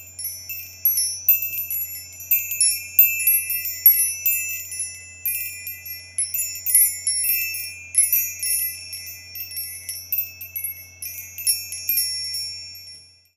mychimes3.aiff